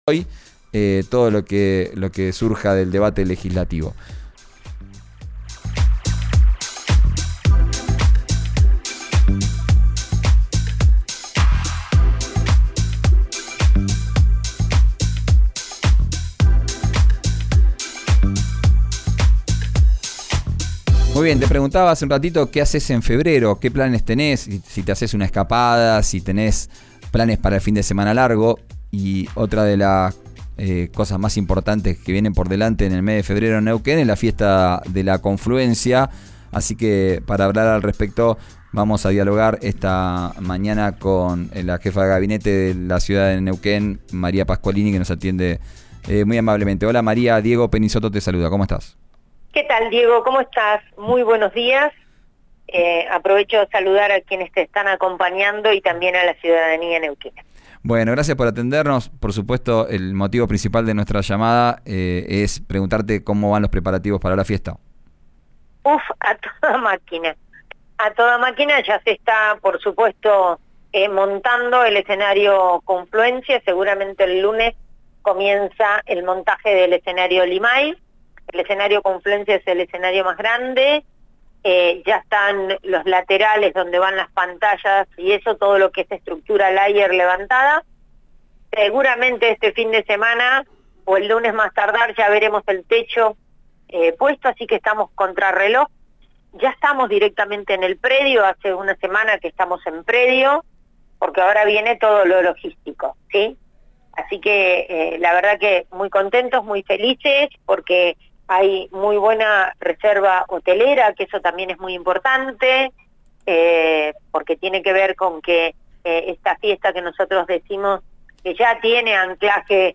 Este domingo se realiza la segunda noche de la Fiesta nacional de la Confluencia 2024 en Neuquén, que tiene lugar en la Isla 132. La jefa de gabinete de la municipalidad y coordinadora del evento, María Pasqualini, detalló a RÍO NEGRO RADIO acerca de las condiciones para el ingreso al predio, así como los objetos no permitidos.